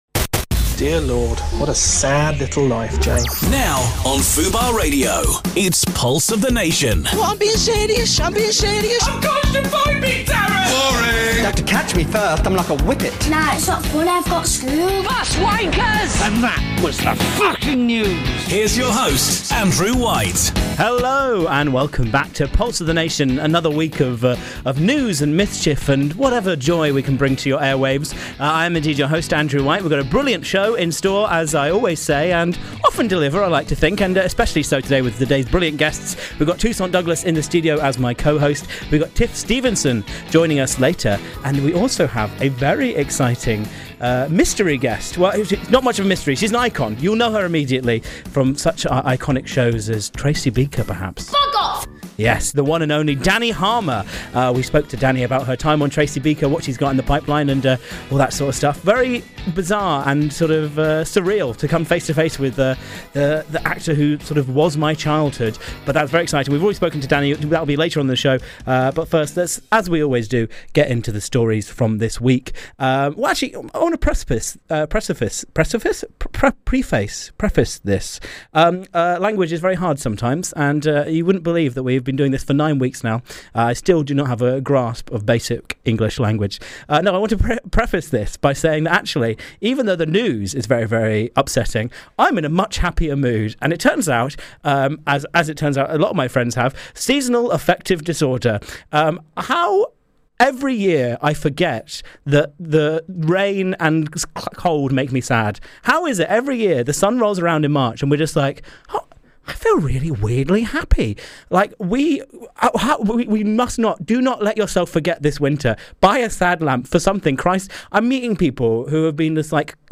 And, the hilarious Tiff Stevenson pops into the studio to chat about her breakout movie Slotherhouse—yes, it’s about a killer sloth!